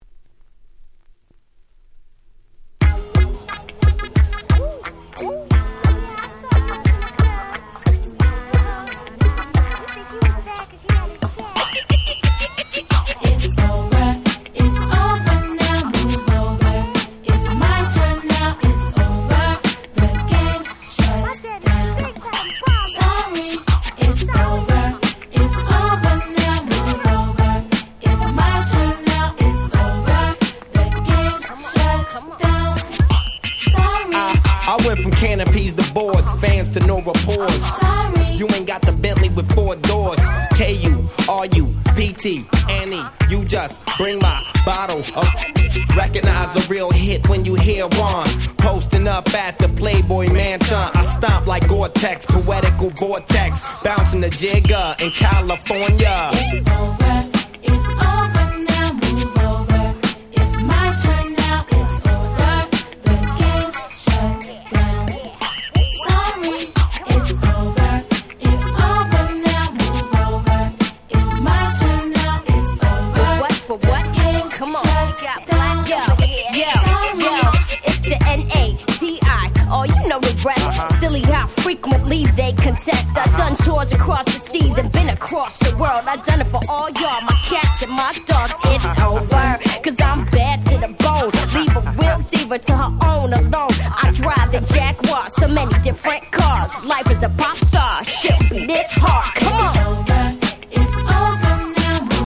01' Smash Hit West Coast Hip Hop !!
キャッチーなサビがここ日本のClubでも大ウケして大ヒットしました！